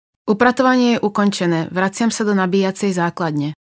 Hovorí po slovensky
Vysávač hovorí v slovenskom jazyku.